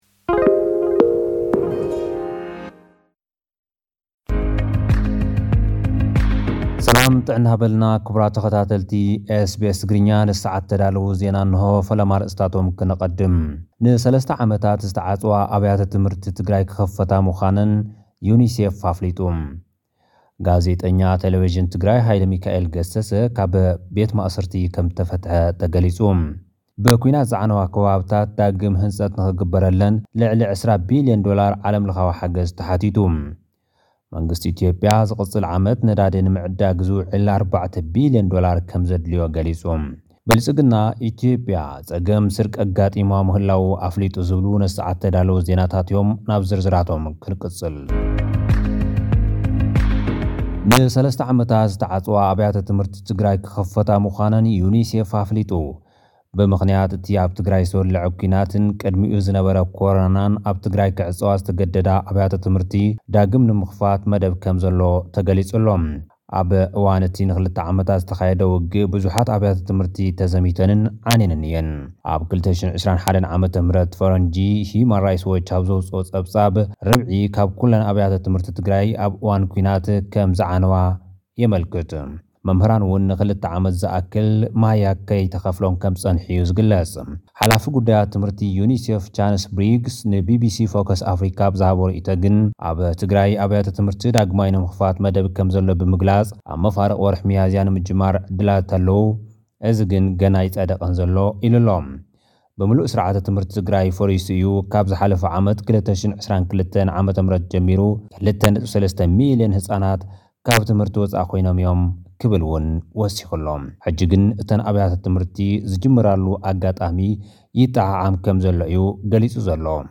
ልኡኽና ካብ ዝለኣኸልና ጸብጻብ ኣርእስታት፦